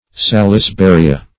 Search Result for " salisburia" : The Collaborative International Dictionary of English v.0.48: Salisburia \Sal`is*bu"ri*a\, n. [Named after R. A. Salisbury, an English botanist.] (Bot.) The ginkgo tree ( Ginkgo biloba , or Salisburia adiantifolia ).